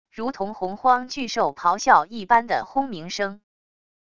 如同洪荒巨兽咆哮一般的轰鸣声wav音频